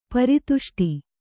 ♪ pari tuṣṭi